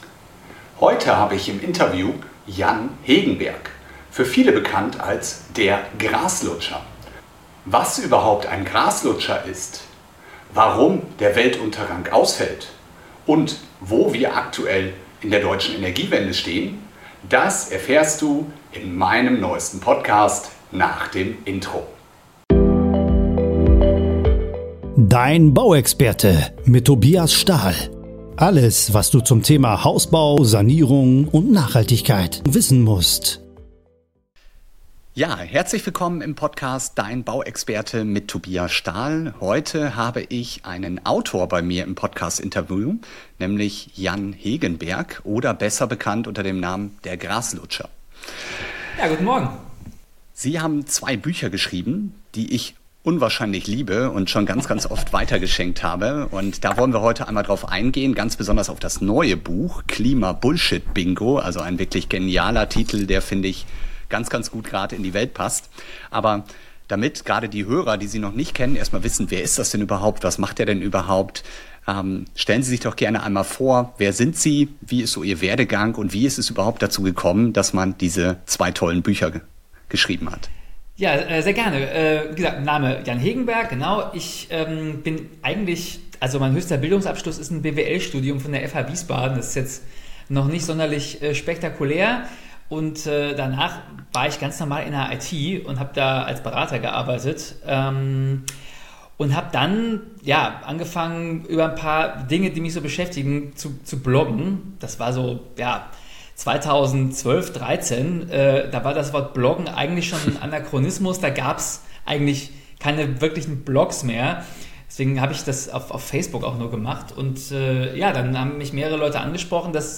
Wenn du zukünftig bei diesen Themen wirklich mitreden willst und auf die ein oder andere Stammtischparole eine clevere Antwort haben möchtest, die alle verblüfft, höre dir dieses Interview an.